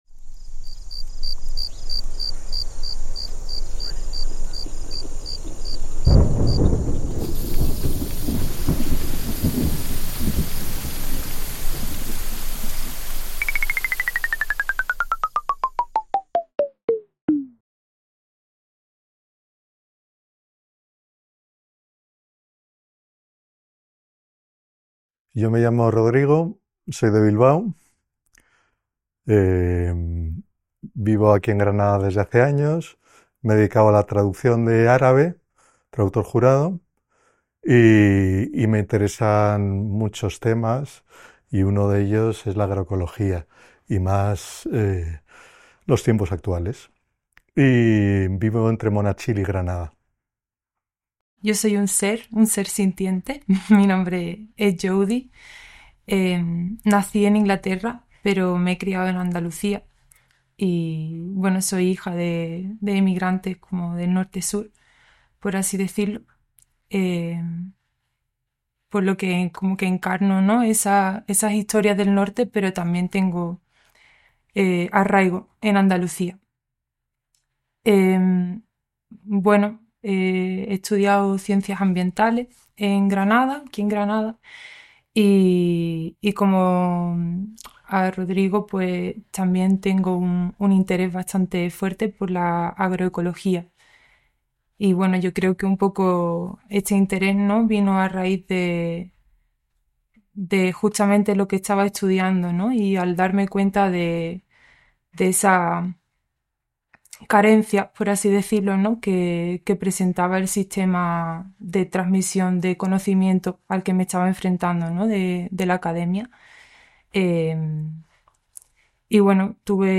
Entrevista realizada en Granada en febrero de 2024.